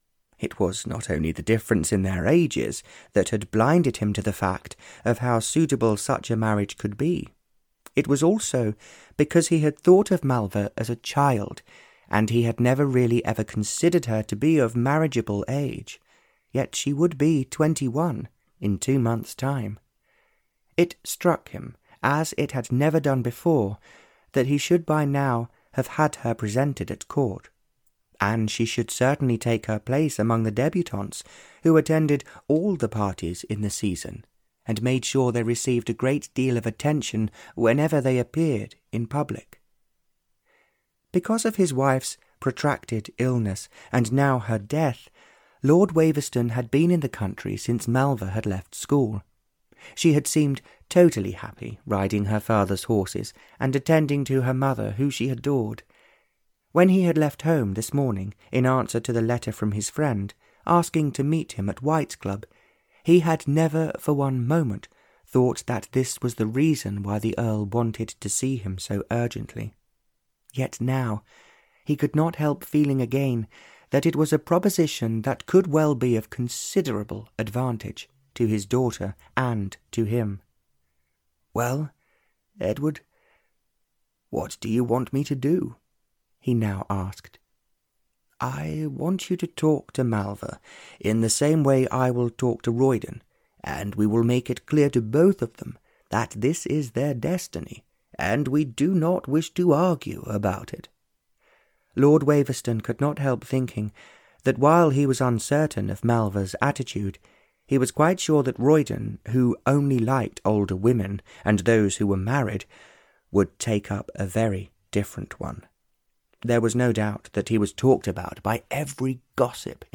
Danger in the Desert (EN) audiokniha
Ukázka z knihy